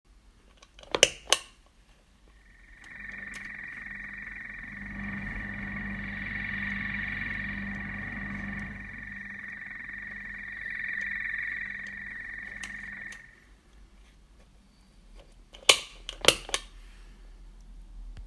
Ja, Level volle Kanne rauscht natürlich, das ist eben unsere Elektrik hier und der nicht abgeschirmte Bass.
Weil ich mich am Anfang gefragt hatte, ob das Rauschen vom Netzteil kommt oder ein Lötdefekt ist, hier meine Soundbeispiele: Level 0-->100%, dann Drive 0-->100%, dann Decade 0--100%, dann alles retour. Netzteile: - Leicke NT33405 (geht sonst gut, bis 15V 1,5A) Anhang anzeigen 511248 - vom Roland Bass Cube RX (war halt da) Anhang anzeigen 511246 Es zwitschert regelrecht!